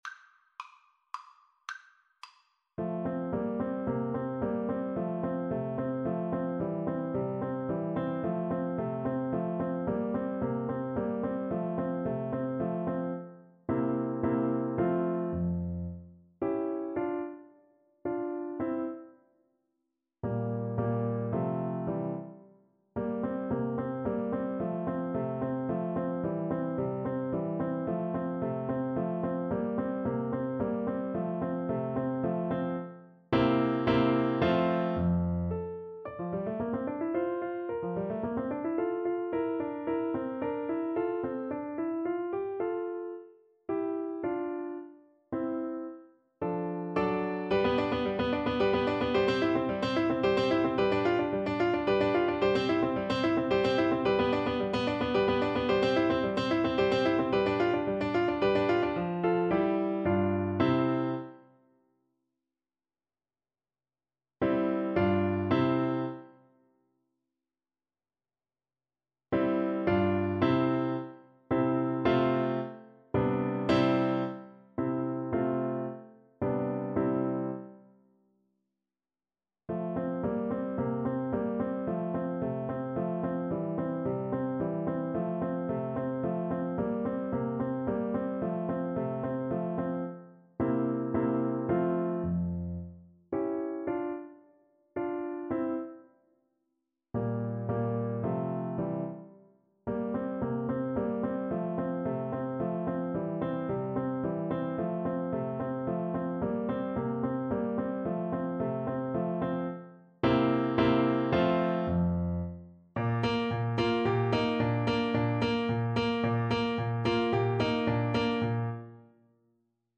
3/4 (View more 3/4 Music)
Classical (View more Classical Flute Music)